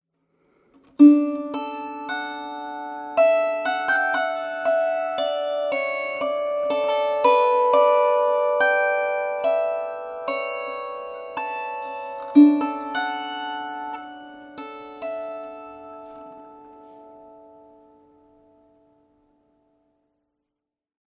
ソプラノライアー「スチューデント」35弦マット | メルヒェン
studentenlier-choroi.mp3